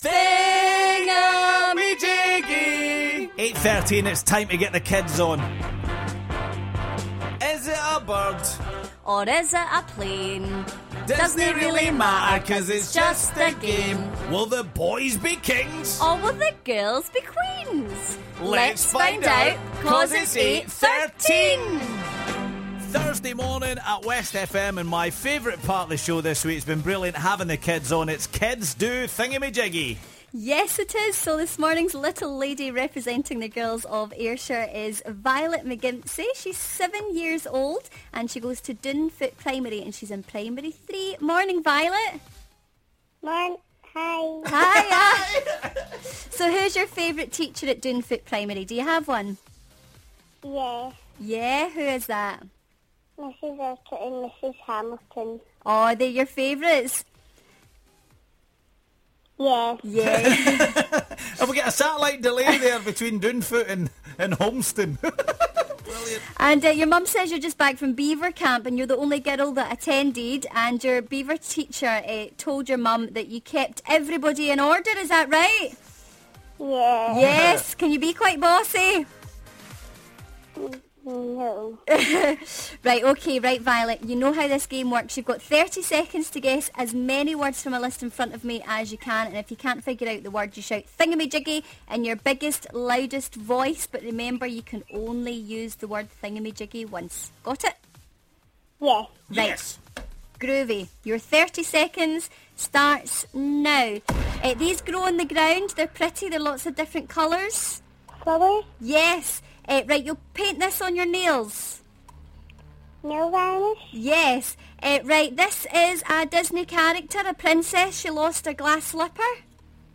All this week on the run up to end of term the kids are taking over Thingummyjiggy.